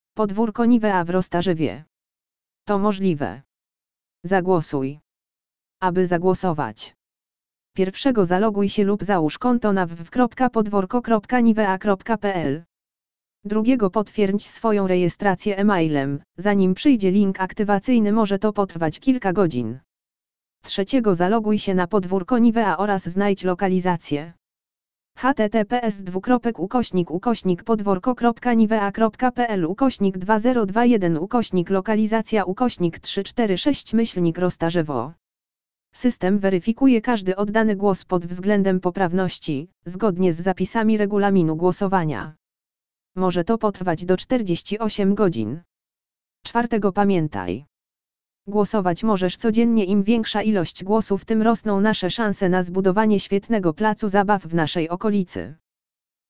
Załączniki LEKTOR AUDIO PODWÓRKO NIVEA W ROSTARZEWIE?
lektor_audio.mp3